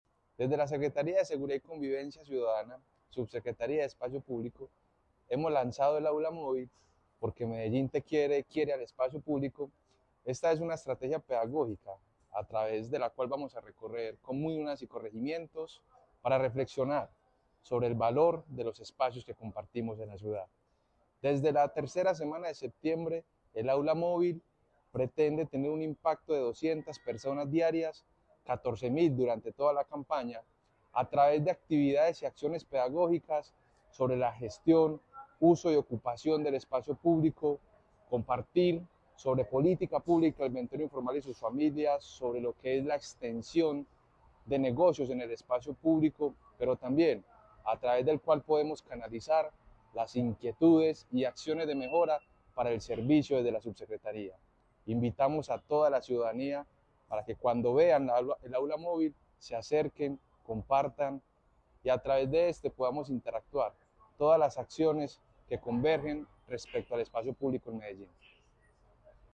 Palabras de David Ramírez, subsecretario de Espacio Público